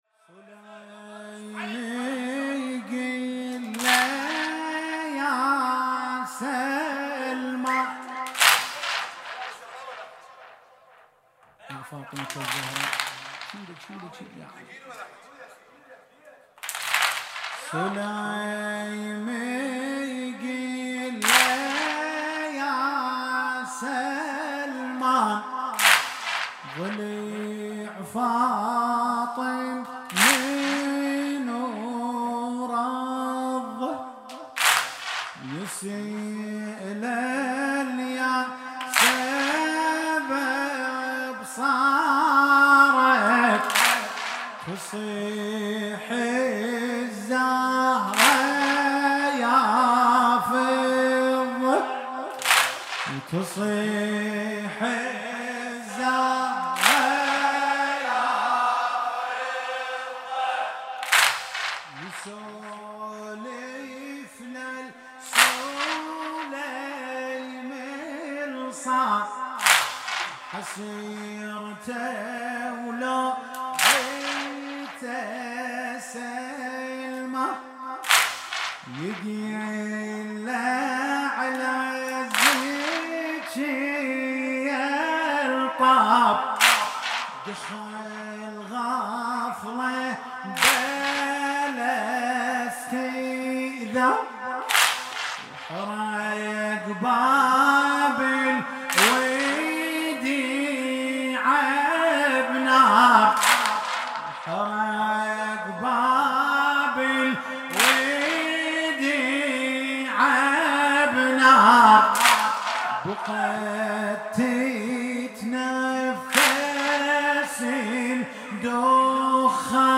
10 اسفند 96 - حسینیه انصار الحسین - مداحی عربی - قسمت دوم
وفات حضرت ام البنین (س)